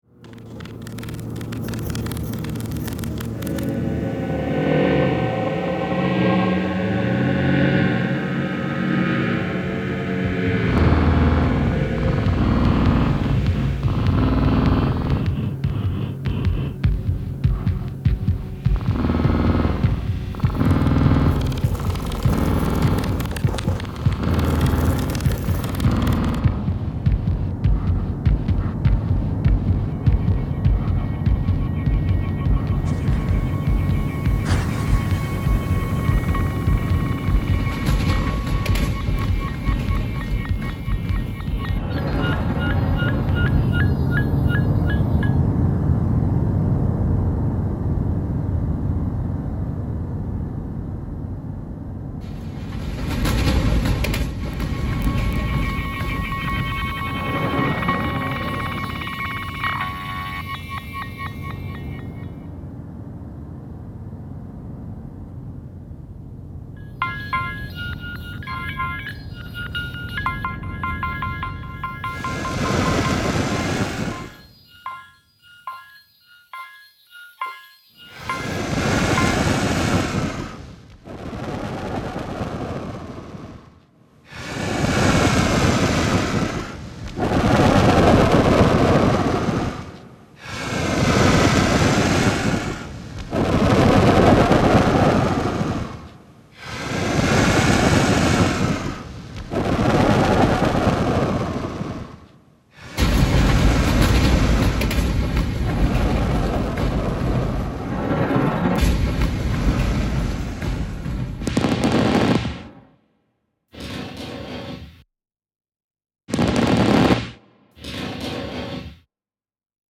stereo version of the multichannel sound installation
Sound Art